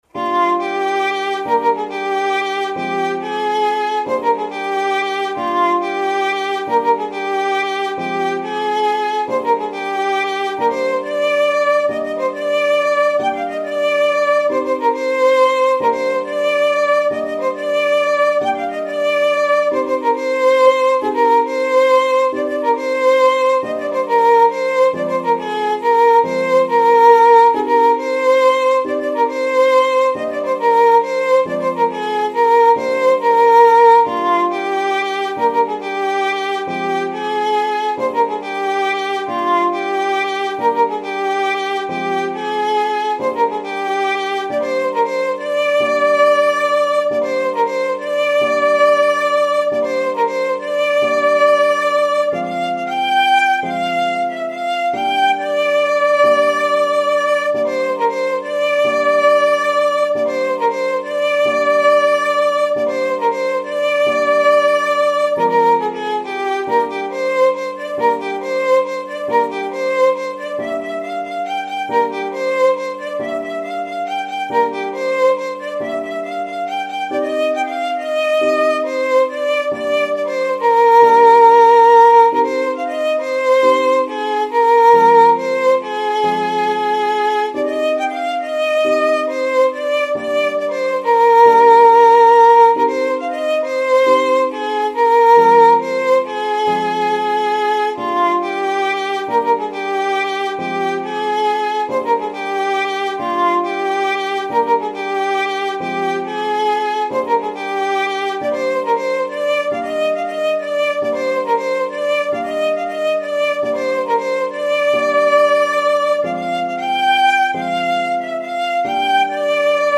سطح : متوسط
ویولون